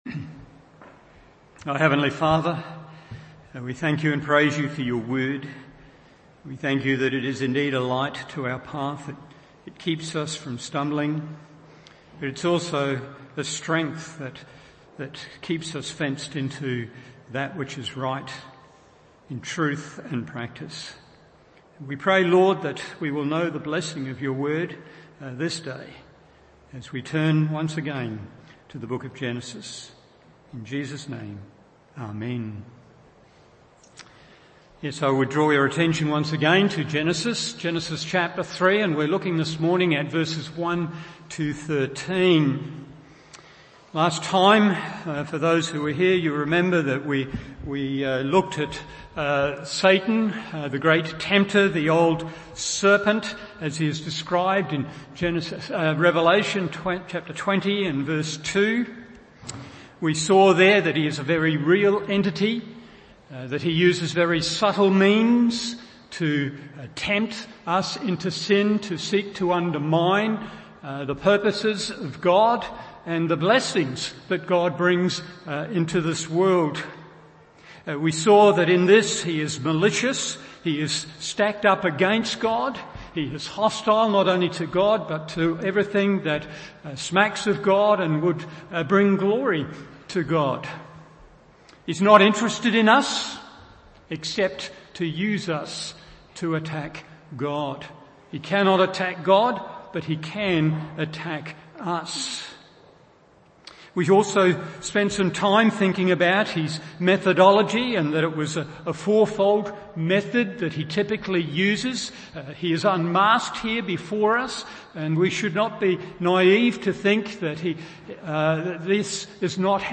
Morning Service Genesis 3:1-13 1. Its Expression 2. Its Effect 3.